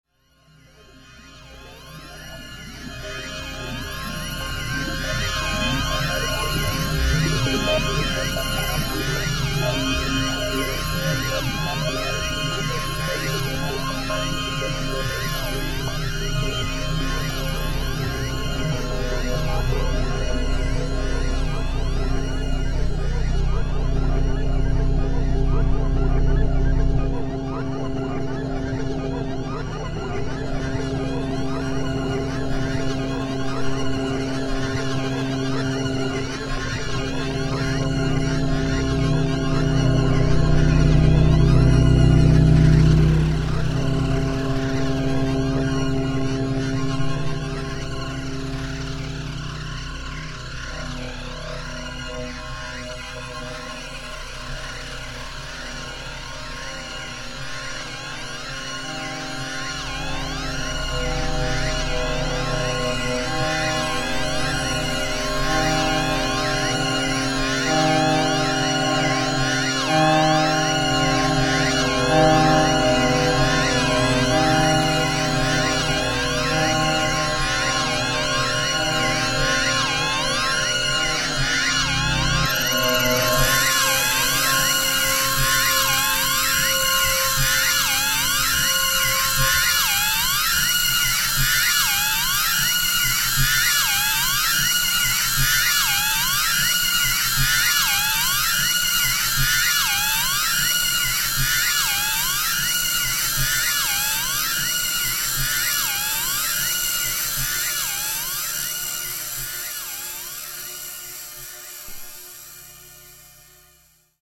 for crackle box & trap